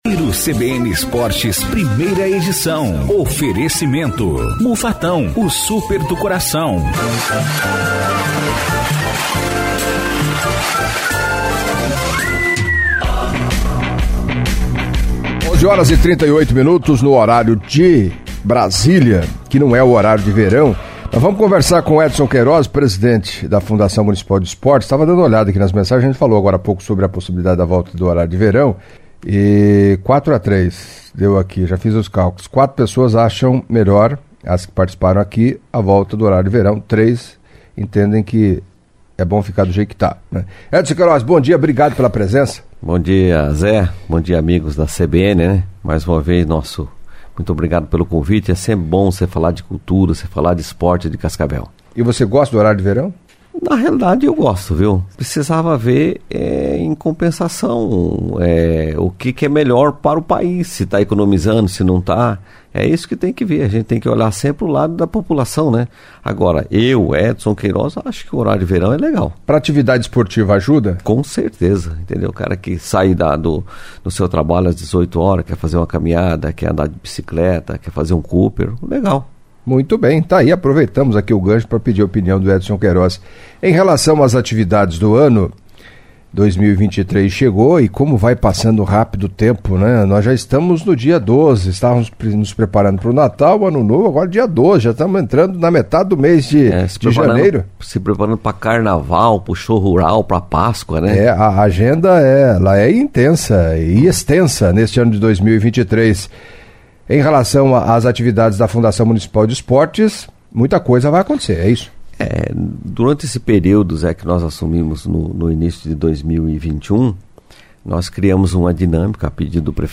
Em entrevista ao Giro CBN Esportes desta quinta-feira (12) o presidente da Fundação de Esporte e Cultura de Cascavel, Edson Queiroz, falou do candedário de atividades 2023, projetos, orçamento, entre outros assuntos, acompanhe.